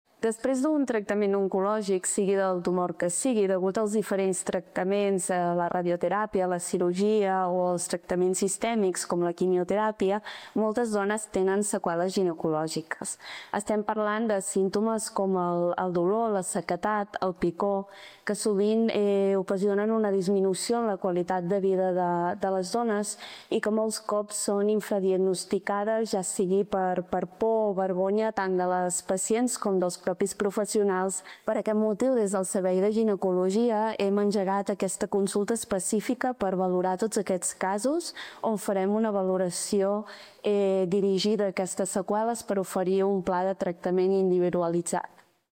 DECLARACIONS DE LA DRA.